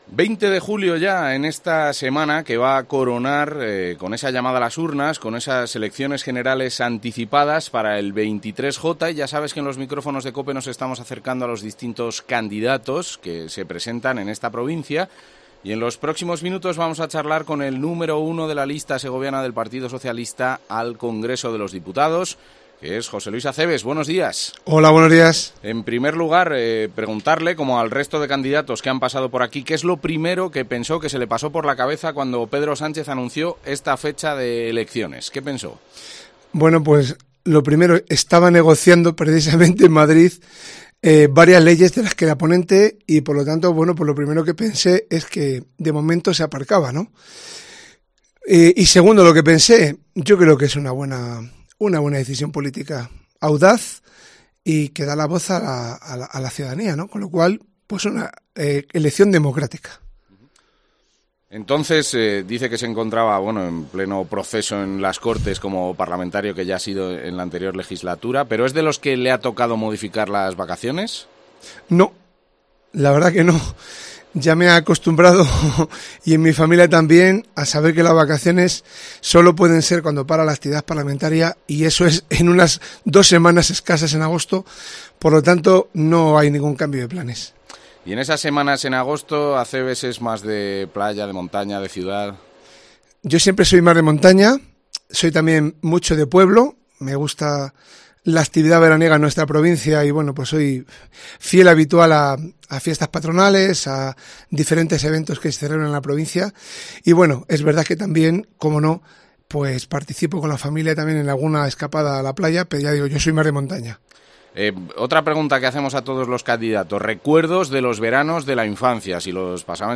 AUDIO: Entrevista al candidato de cara al 23-J